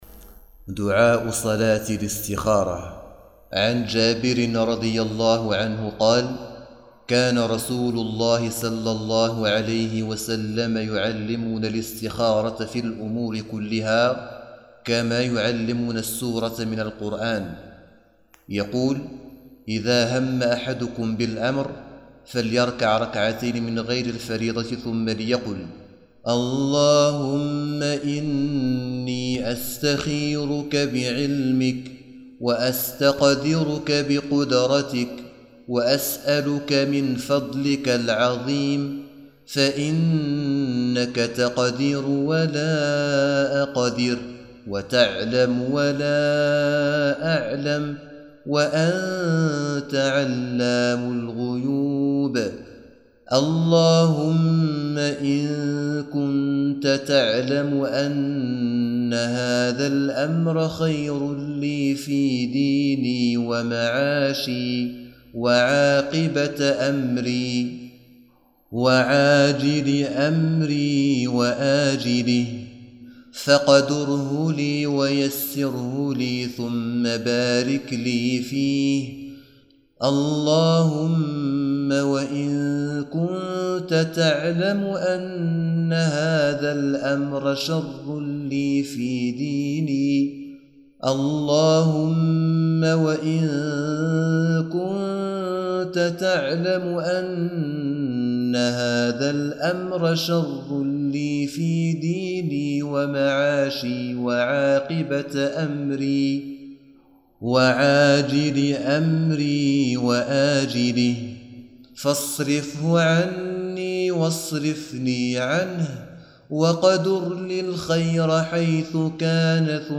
تلاوة لدعاء صلاة الاستخارة كاملاً